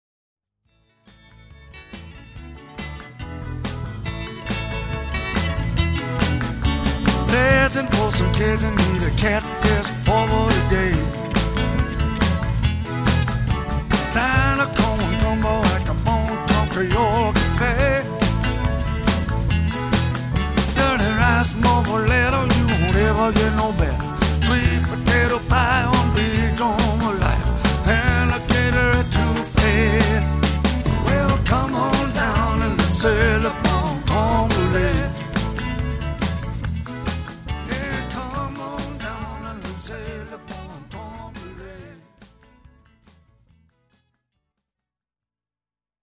Since I'm a musician and have my own fully equipped studio, I've created brief tunes for my main pages and also provided samples of the music I play on stage. I also created a theme song for the Bon Temps Creole Cafe in San Luis Obispo, California which, not surprisingly, has a Cajun flavor.